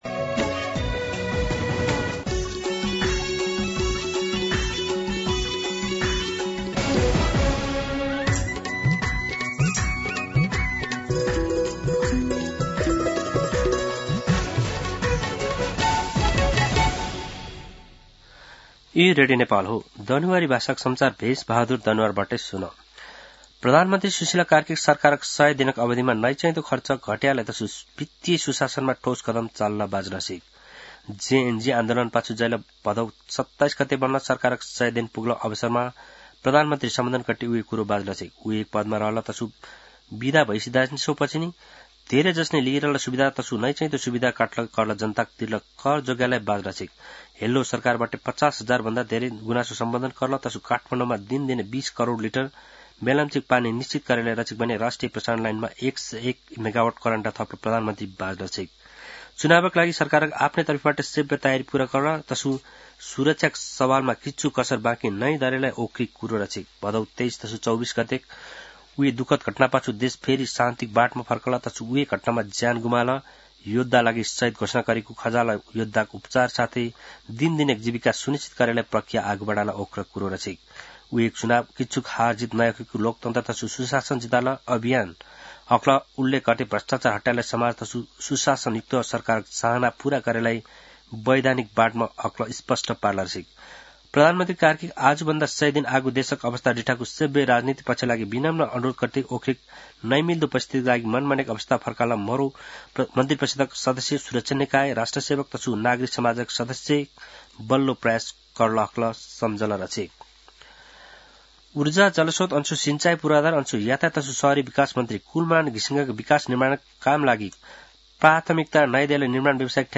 दनुवार भाषामा समाचार : ५ पुष , २०८२